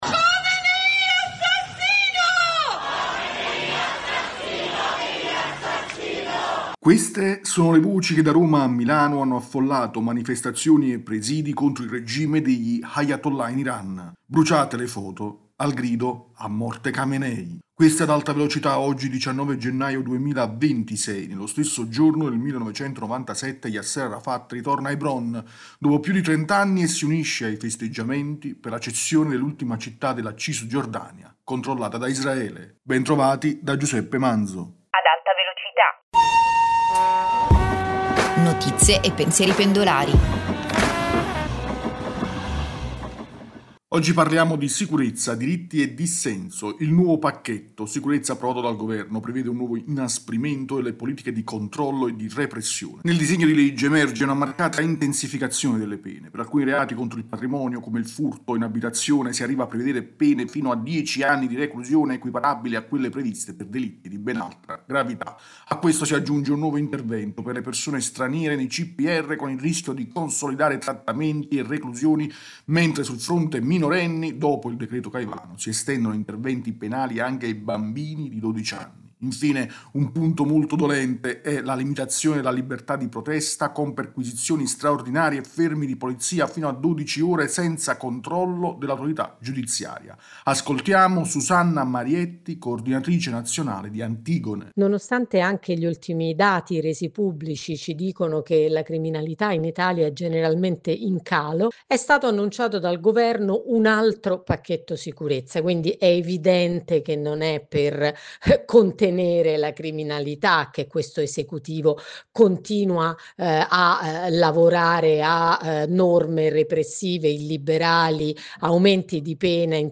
Ascolta Ad Alta Velocità, rubrica quotidiana